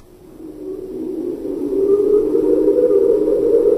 • wind howling 3.ogg
[wind-howling]-_sbo.wav